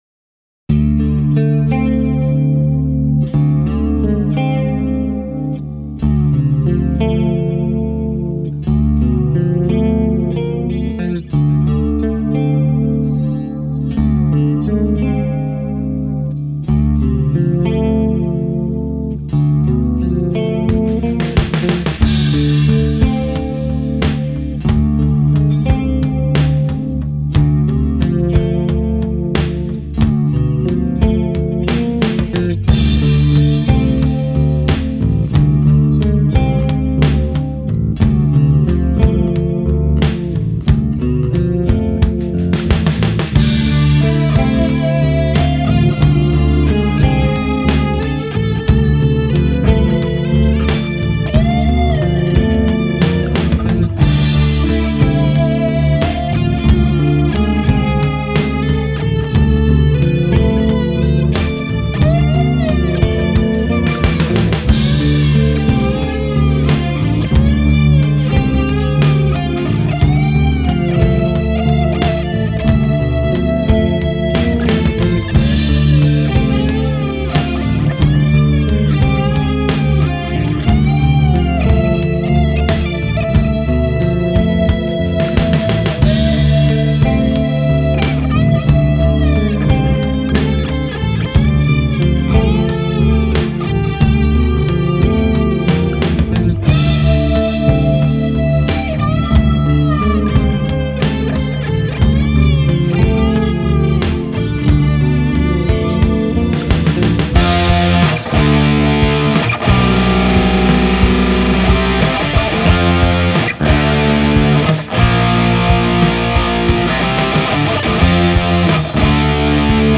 I think it really shows off the advantages of a multi-scale guitar as the low strings have some nice piano-like qualities while the high strings bend like nobody's business. It's not too hard to do a major third bend on this guitar and as this clip demonstrates, it's not at all difficult to do bends of a minor third.
Bass was my cheapo Ibanez and guitar and bass were both recorded direct through an AxeFx.
Wow....those low notes are killer sounding on the clean.
Fantastic tone on the leads. Everything is really crystal clear.
Yeah, that lead tone is sweet. Nice liquid and creamy tone.
Apparently, he felt the song needed chorus, and I agree with that.
I dont usually like clean stuff but really dig the clean tone and soloing